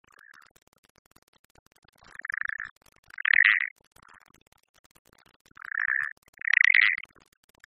Le mâle chante, la femelle également mais son appel (qui est une réponse au stimulus mâle) est nettement plus discret.
chant:
chant pelodyte.mp3